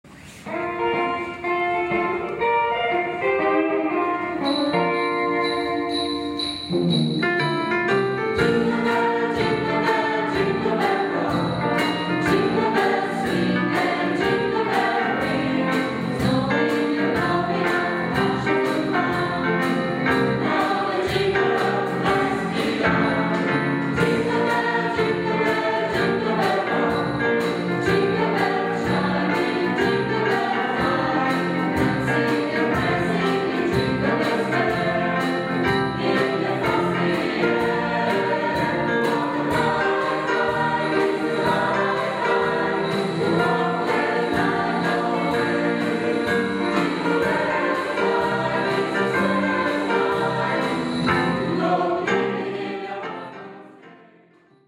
Natalizio